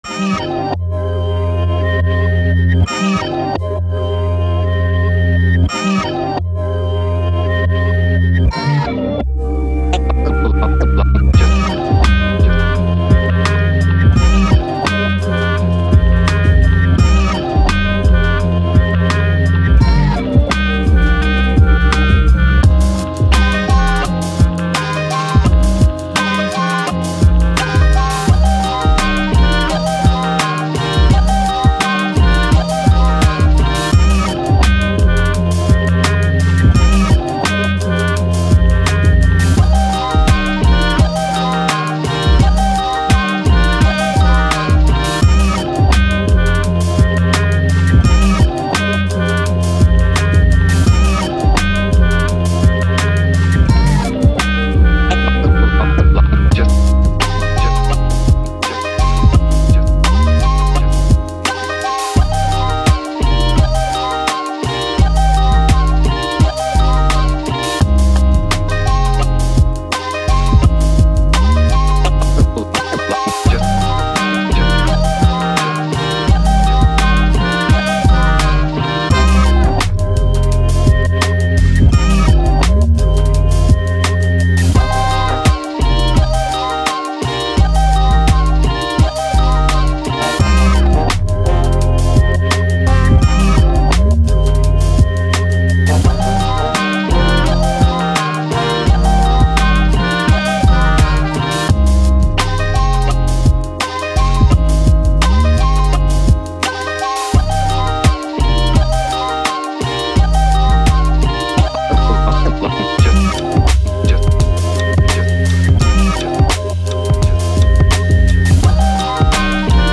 Hip Hop, Boom Bap, Confident, Upbeat